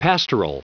Prononciation du mot pastoral en anglais (fichier audio)
Prononciation du mot : pastoral